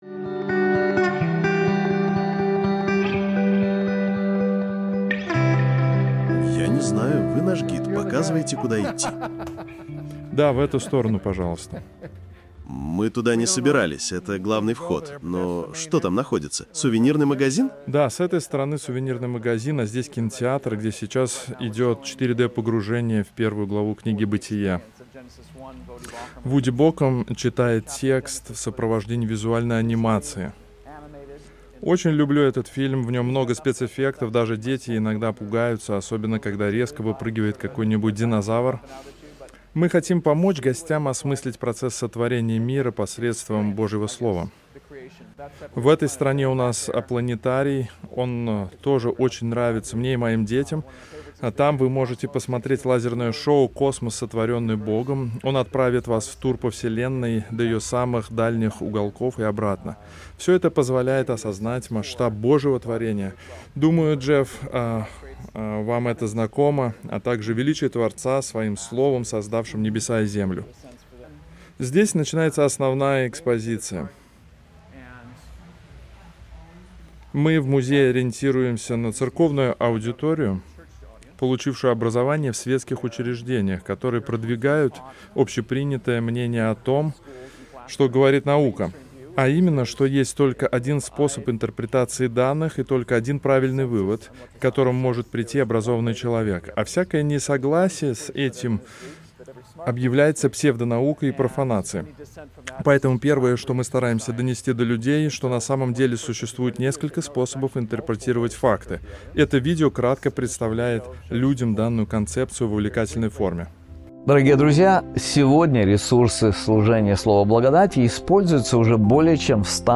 Экскурсия по Музею Сотворения